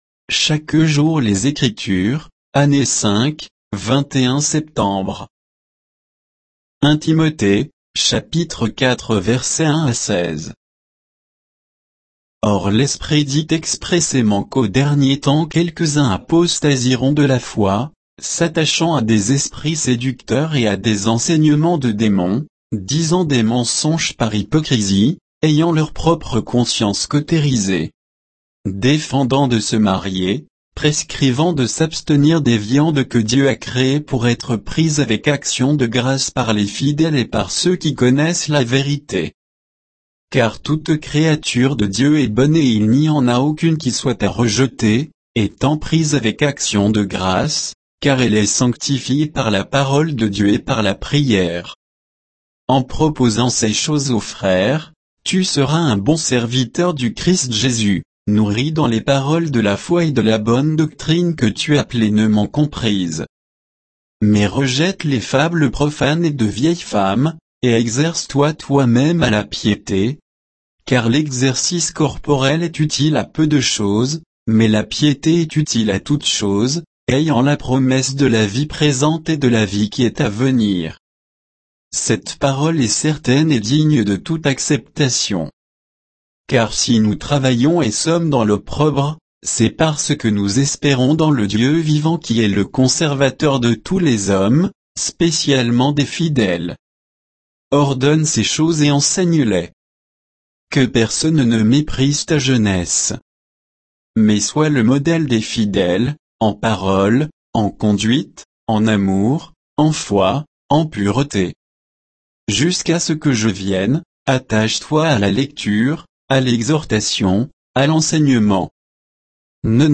Méditation quoditienne de Chaque jour les Écritures sur 1 Timothée 4